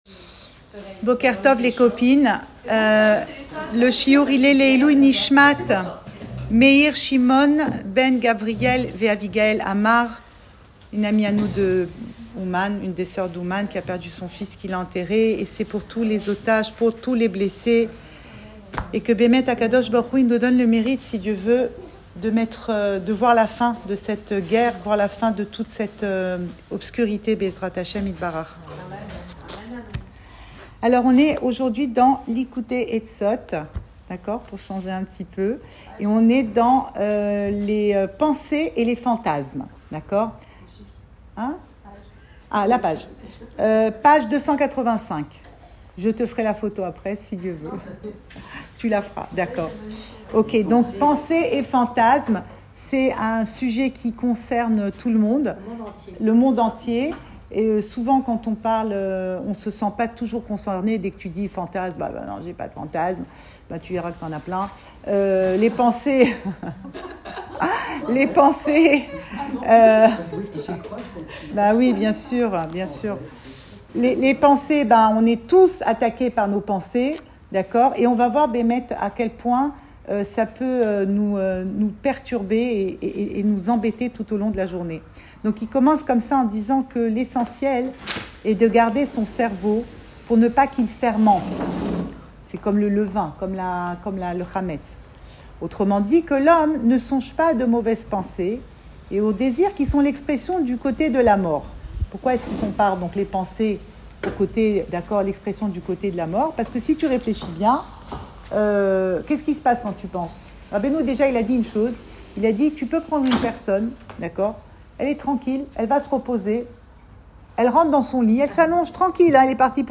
Cours audio Emouna Le coin des femmes Le fil de l'info Pensée Breslev - 11 juillet 2025 11 juillet 2025 La pensée dans tous ses états. Enregistré à Tel Aviv